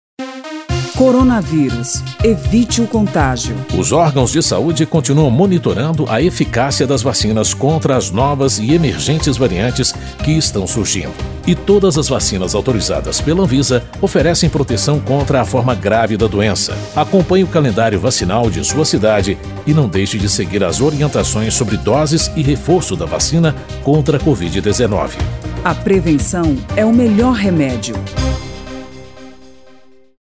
spot-vacina-coronavirus-3-1.mp3